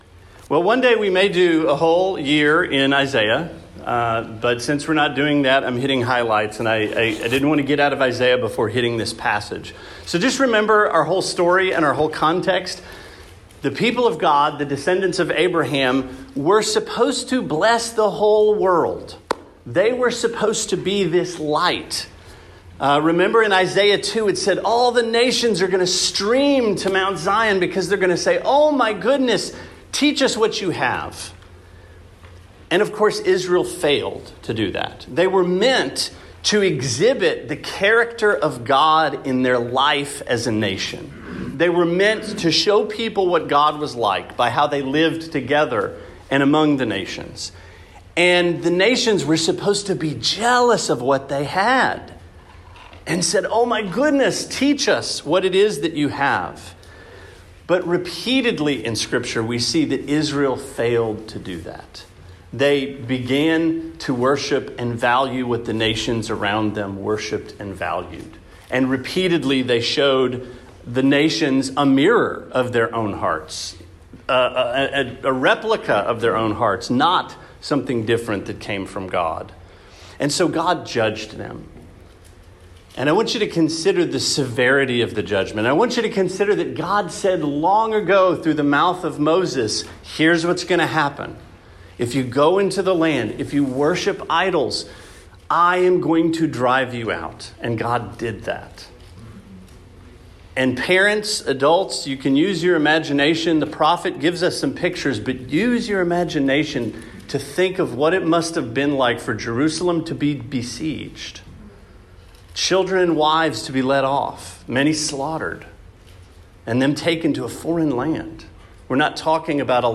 Sermon 3/26: Where is Your Trust?